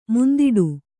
♪ mundiḍu